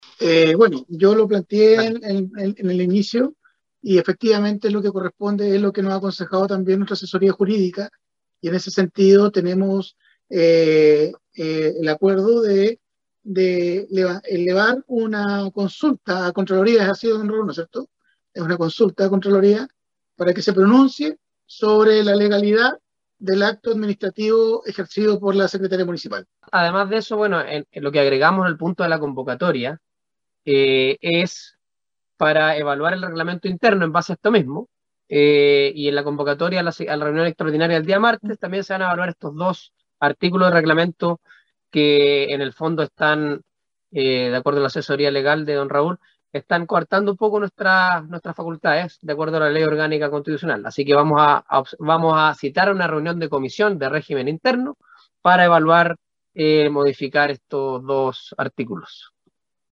Ante esta situación, el viernes por la noche efectuaron una conferencia de prensa virtual donde se refirieron a lo sucedido durante ese día.
30-CONCEJALES-ANDRES-IBANEZ-SAMUEL-MANDIOLA.mp3